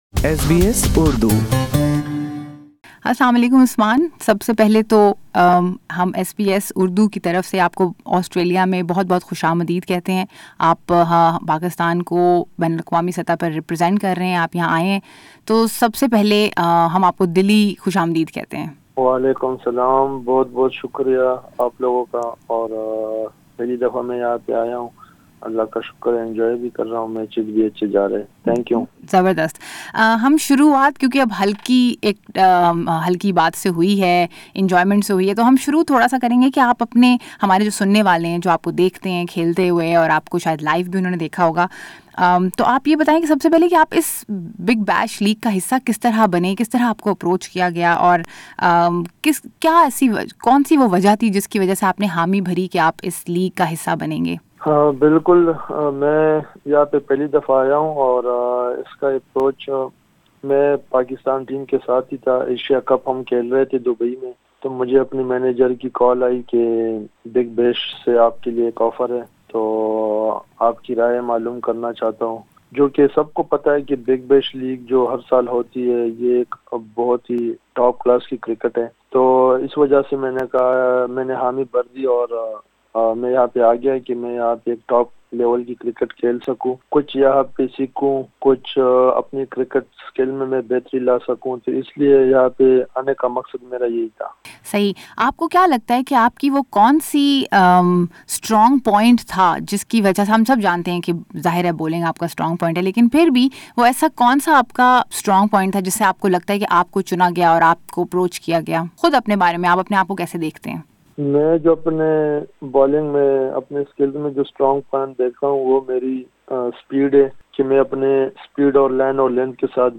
Pakistani fast bowler, Usman Khan Shinwari, played for Melbourne Renegades at the Big Bash League this year. He spoke to SBS Urdu about his experience and his first-time in Australia.